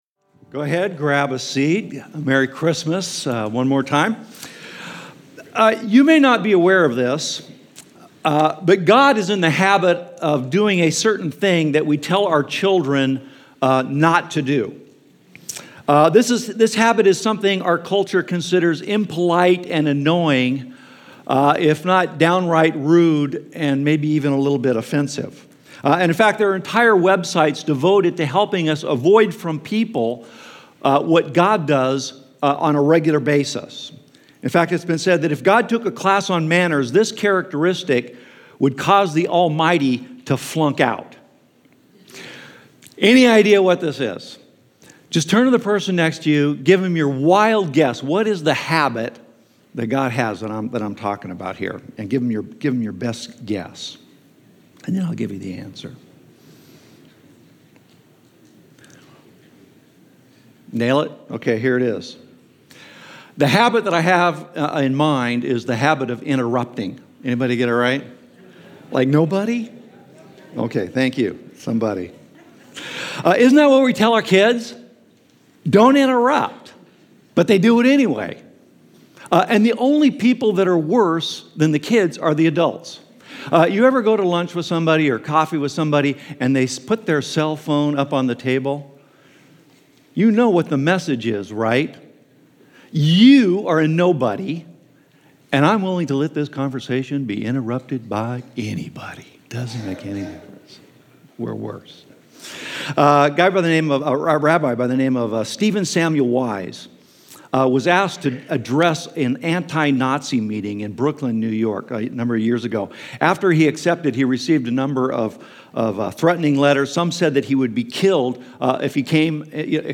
Christmas-Sunday-Morning.mp3